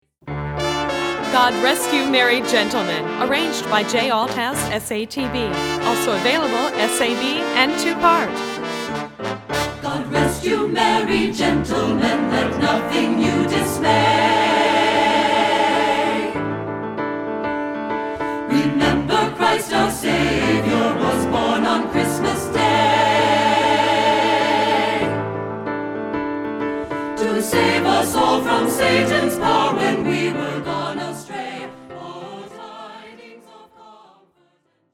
Composer: English Carol
Voicing: Instrumental Parts